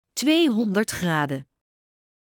Alle functies van deze airfryer worden uitgesproken met een duidelijke vrouwenstem.